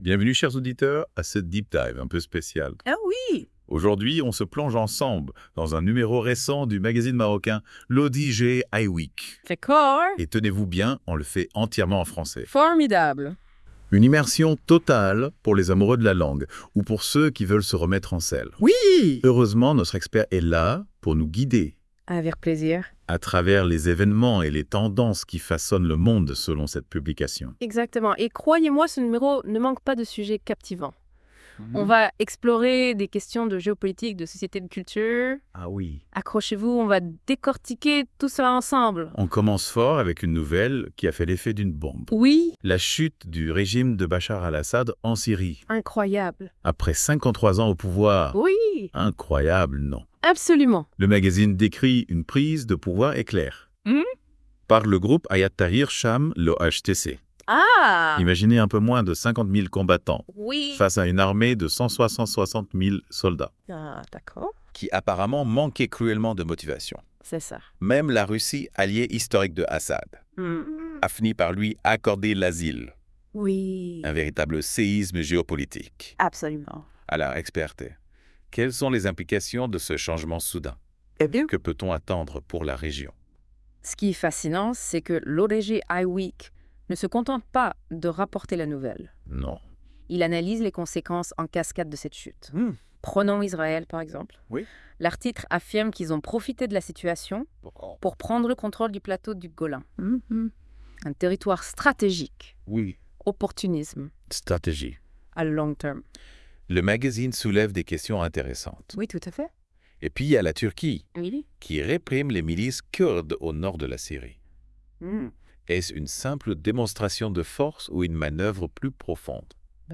+ Débat - Podcast : les chroniqueurs de la Web Radio débattent des idées contenues dans cet Hebdomadaire à travers ces questions